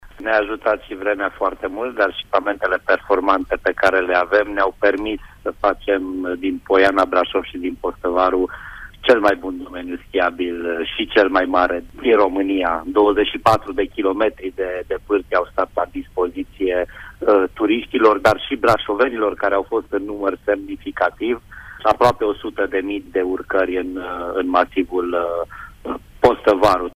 Recordul s-a înregistrat sâmbăta trecută. Vremea favorabilă și zăpada de pe pârtiile de schi și săniuș au atras aproape 100.000 de vizitatori, spune administratortul public al municipiului Brașov, Miklos Gantz: